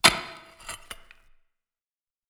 ajout des samples de pioche
pickaxe_3.wav